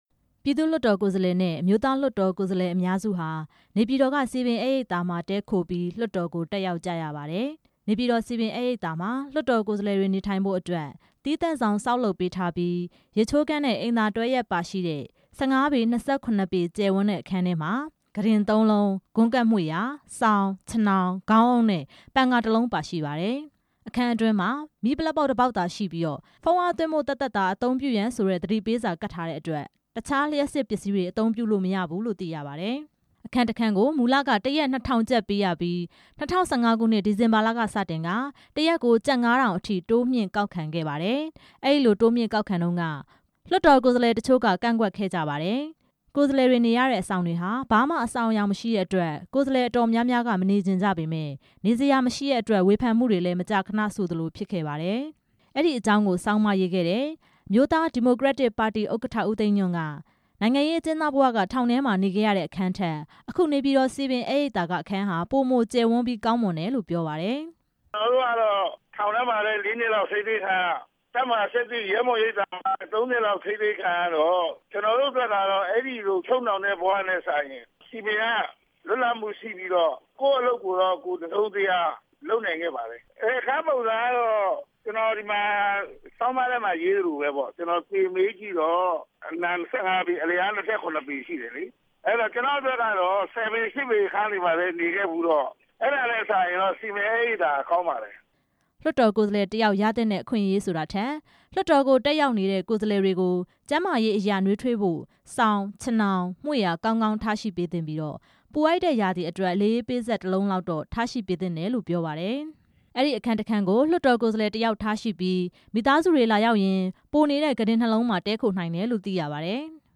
လွှတ်တော်ကိုယ်စားလှယ်တွေ နေထိုင် စားသောက်မှု ဆင်းရဲတဲ့အကြောင်း ပထမအကြိမ် လွှတ်တော်ကိုယ်စားလှယ်ဟောင်းတွေရဲ့ ပြောကြားချက်တွေကို